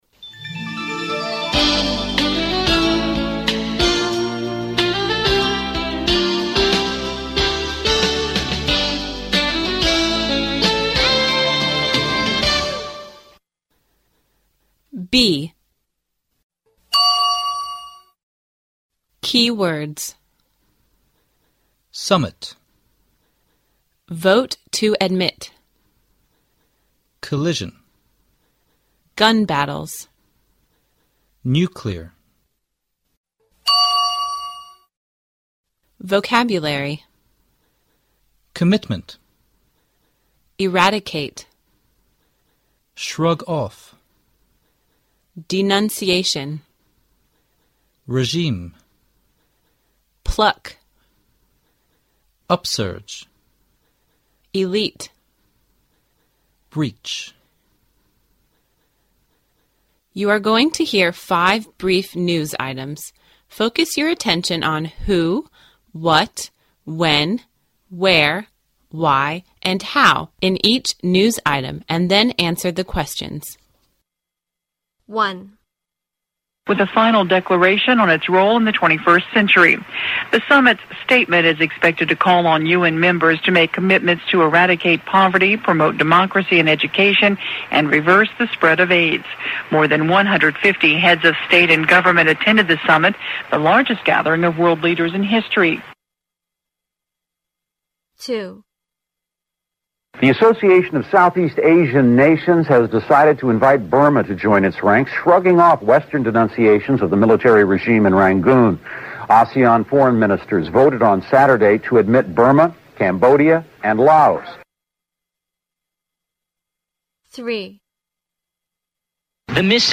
You're going to hear five brief news items.Focus your attention on who, what, when, where, why and how in each news item, and then answer the questions.